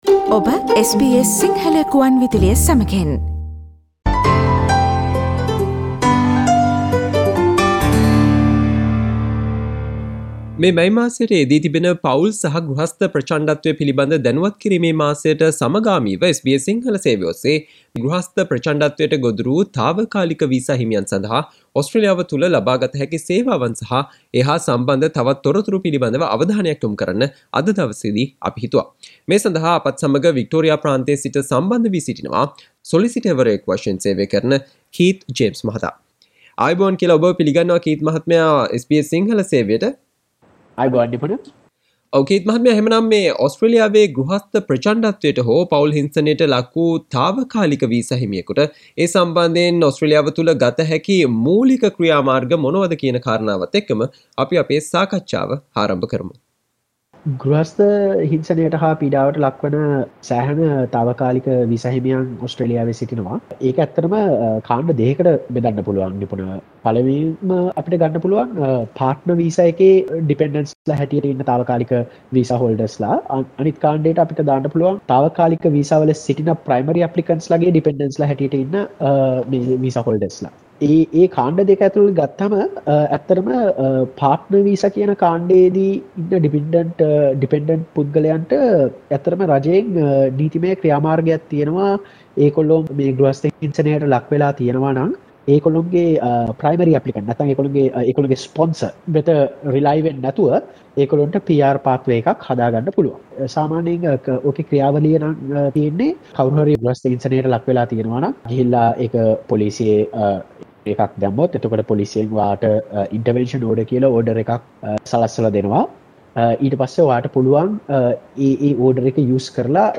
ගෘහස්ථ හෝ පවුල් ප්‍රචණ්ඩත්වයට ගොදුරු වූ තාවකාලික වීසා හිමියන් සඳහා ඔස්ට්‍රේලියාව තුල ලබාගත හැකි සේවාවන් සහ ඒ හා සම්බන්ධ තොරතුරු පිළිබඳ SBS සිංහල ගුවන් විදුලිය සිදුකළ සාකච්ඡාවට සවන් දෙන්න.